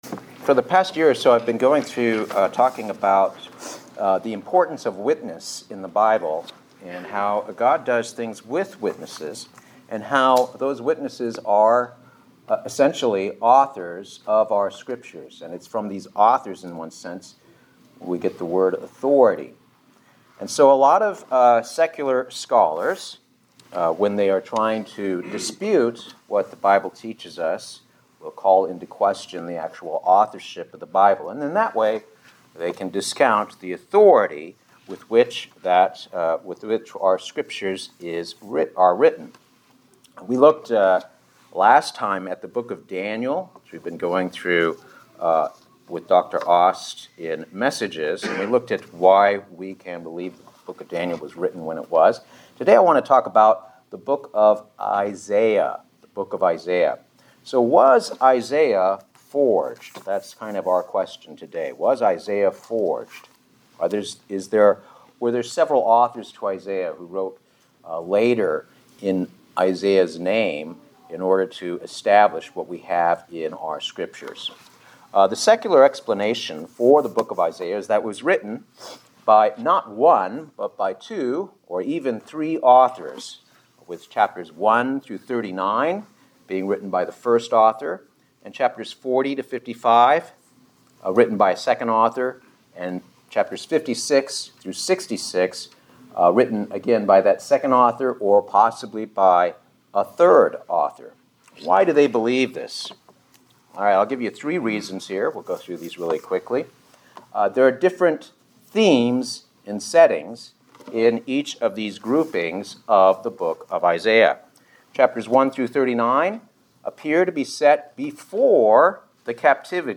Sermons
Given in Buford, GA